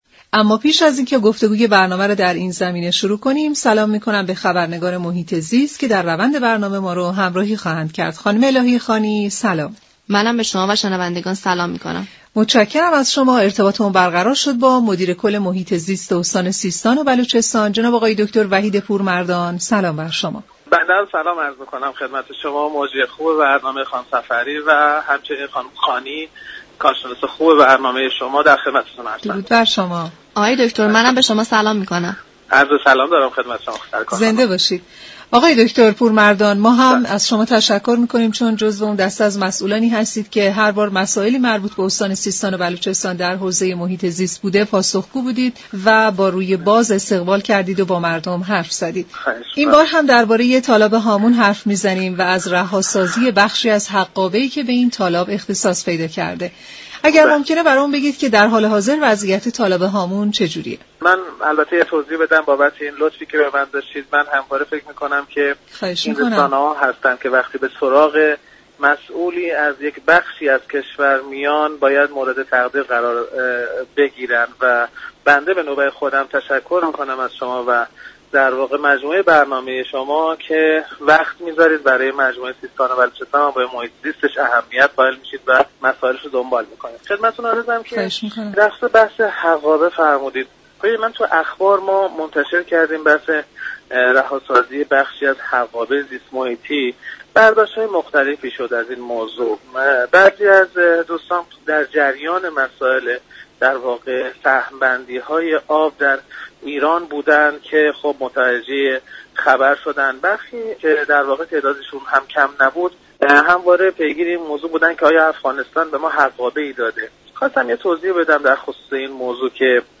به گزارش شبكه رادیویی ایران، دكتر وحید پورمردان مدیر كل اداره محیط زیست استان سیستان و بلوچستان در گفت و گو با برنامه «سیاره آبی» درباره حقابه تالاب هامون گفت: طبق قرارداد امضا شده میان ایران و افغانستان، از سال 51 حقابه تالاب هامون از رودخانه هیرمند حدود 820 میلیون متر مكعب است.